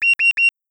warning_immediate.wav